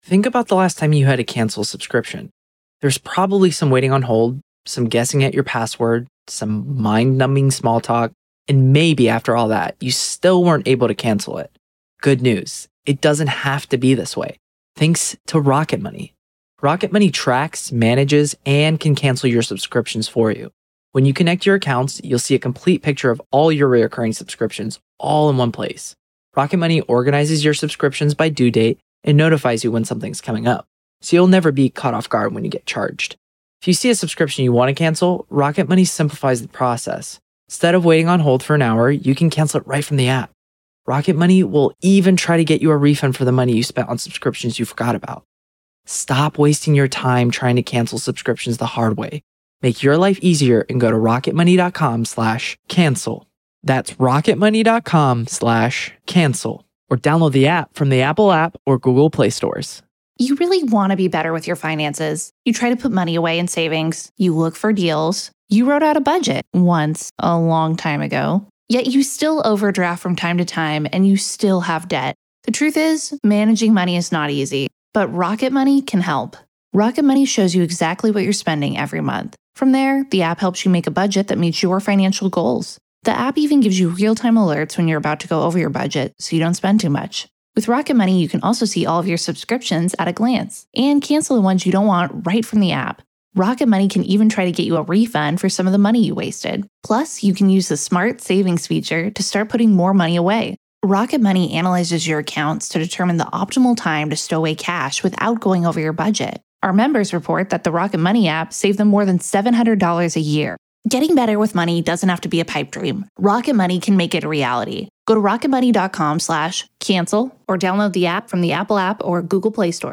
Today's bulletin with reports from FSN's bureaus in Washington, London and Paris.